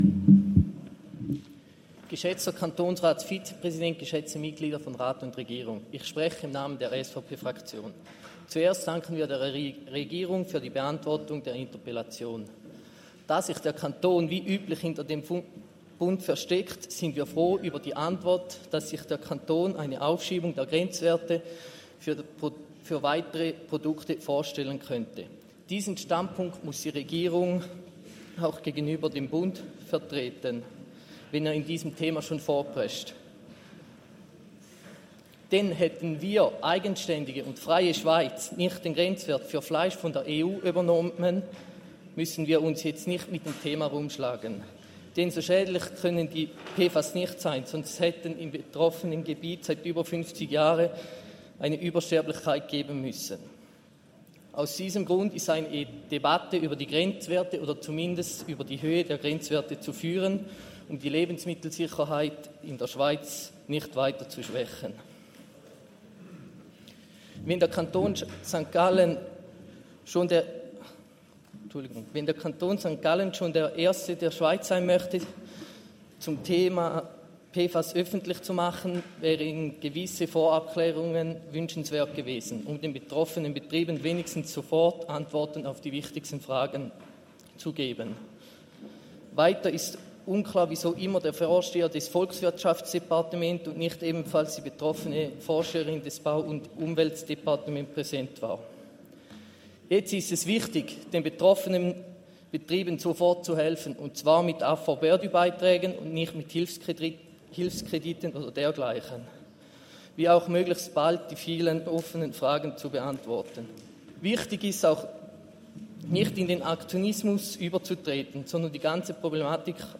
Session des Kantonsrates vom 16. bis 18. September 2024, Herbstsession